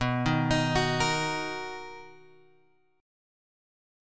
B Major 7th